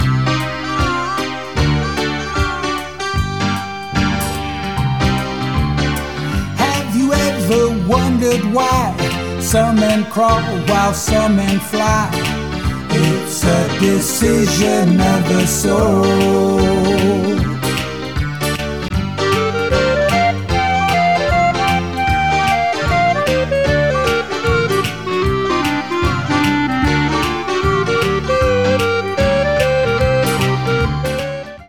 ST Duet with Clarinet
SA Duet with Flute